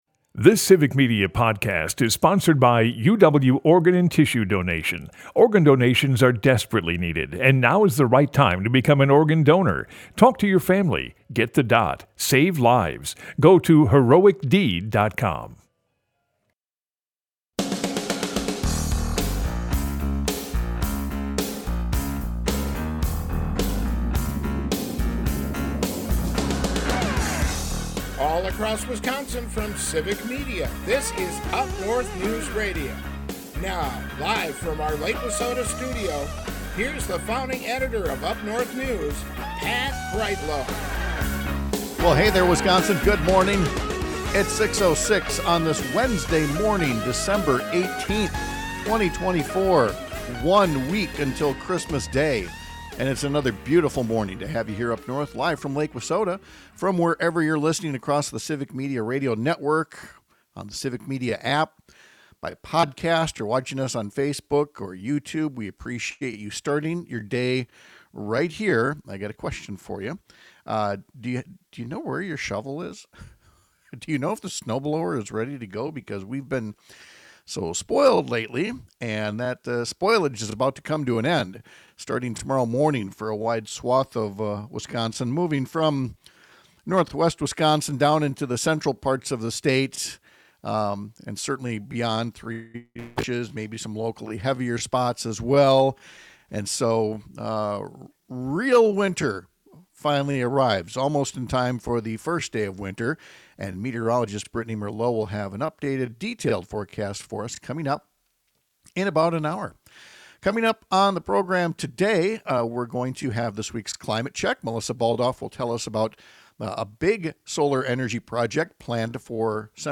Broadcasts live 6 - 8 a.m. across the state!